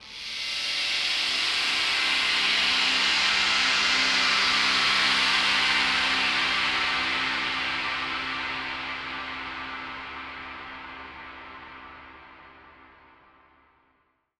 SaS_HiFilterPad05-C.wav